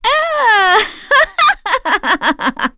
Erze-Vox_Happy2.wav